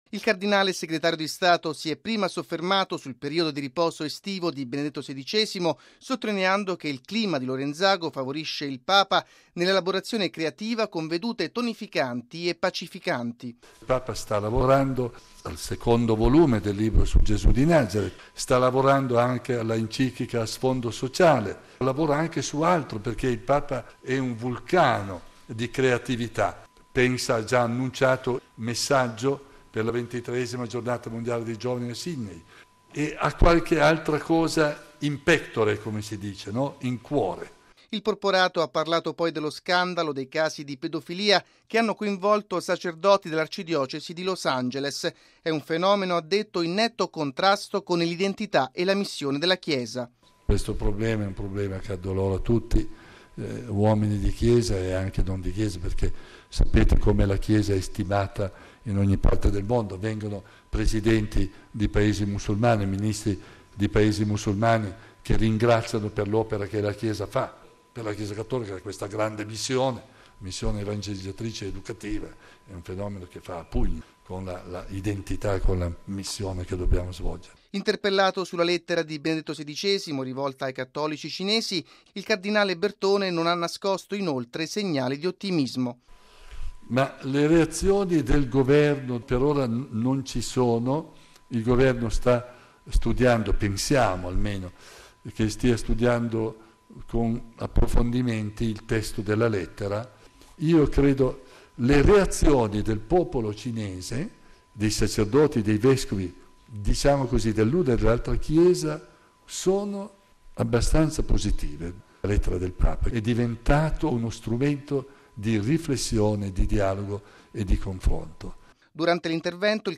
Il porporato, nel pomeriggio, ha tenuto una conferenza stampa a tutto campo a Pieve di Cadore. Dalle crisi internazionali alla questione del risarcimento per i casi di pedofilia a Los Angeles; dalla Lettera del Santo Padre ai cattolici cinesi al recente viaggio apostolico in Brasile, il cardinale Tarcisio Bertone ha affrontato molti temi di attualità per la vita della Chiesa.